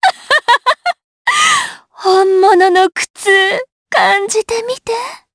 DarkFrey-Vox_Skill3_jp.wav